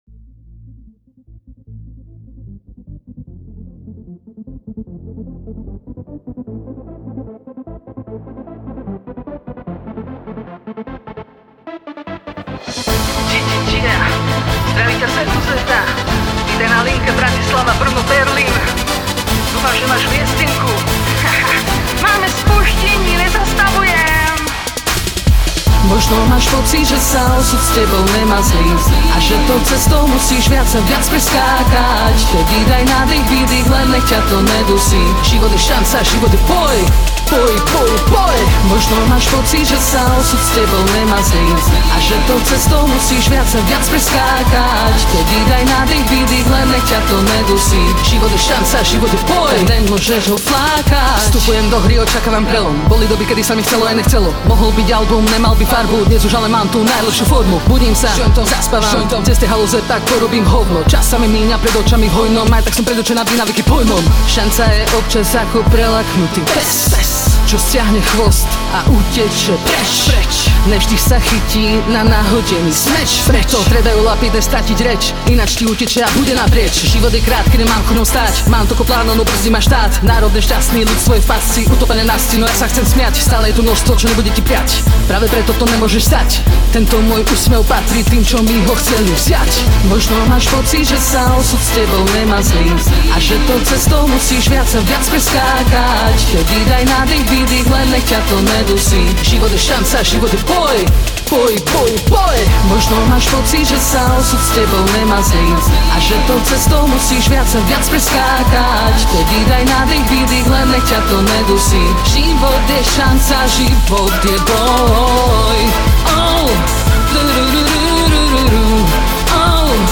hip-hopovej